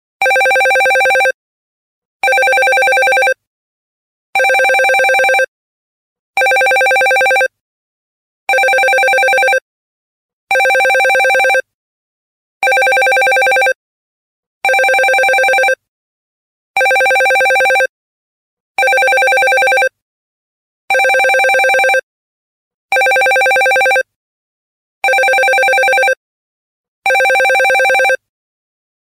business-ring_24912.mp3